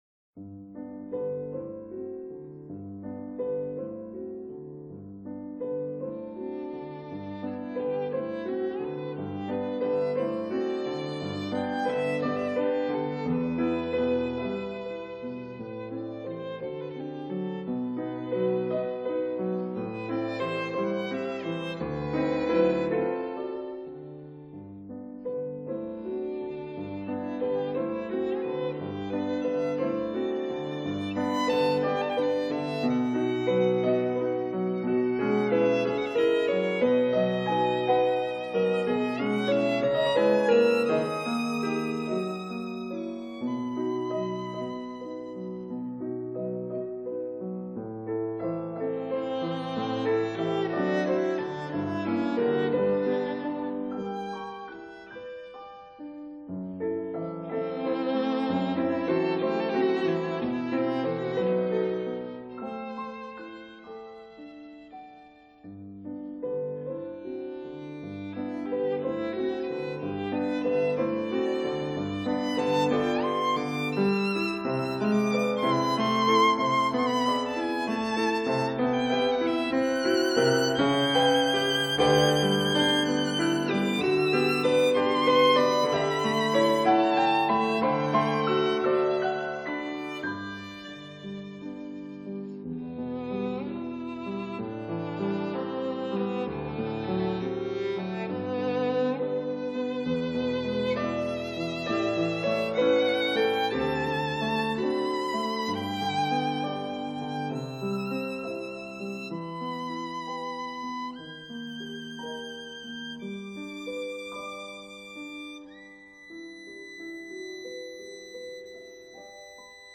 24 pieces for violin & piano, Op. 50